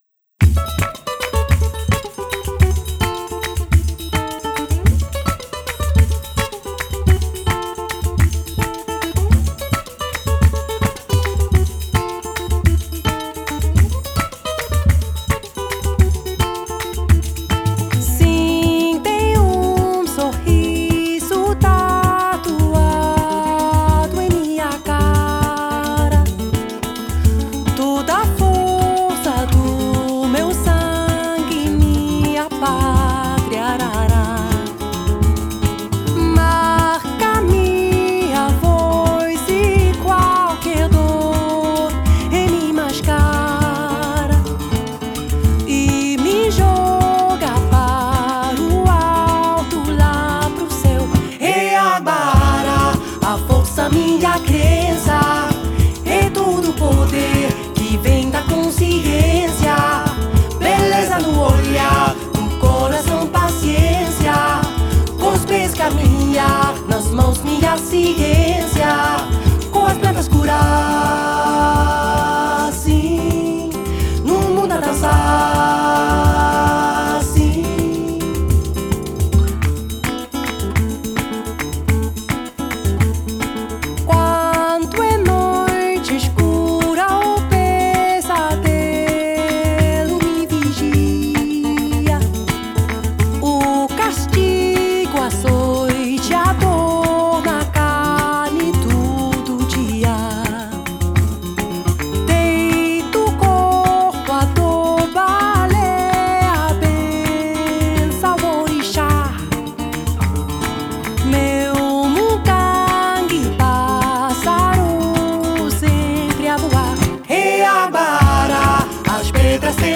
mixing Swedish traditional songs with Brazilian rhythms
7 string acoustic guitar
percussion